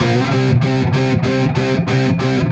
Index of /musicradar/80s-heat-samples/95bpm
AM_HeroGuitar_95-C01.wav